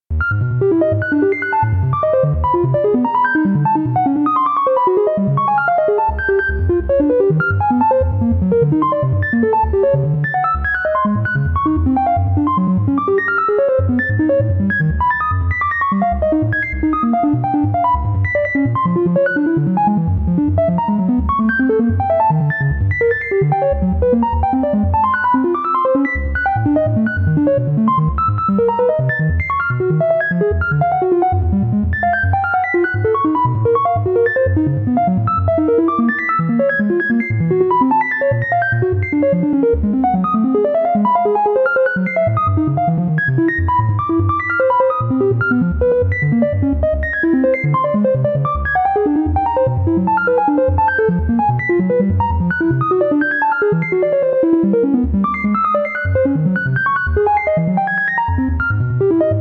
ogg(L) 電子音 ランダム ロボット
生成した乱数を音符にひたすら入力する作曲とは違う何かでした。